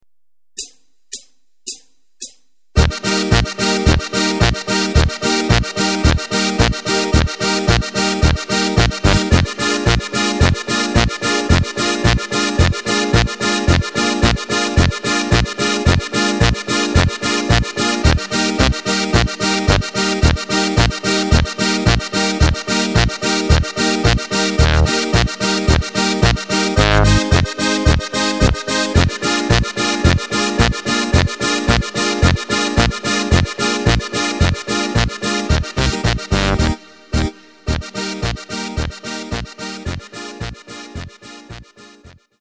Takt: 2/4 Tempo: 110.00 Tonart: Eb
Polka für Steirische Harmonika!
mp3 Playback Demo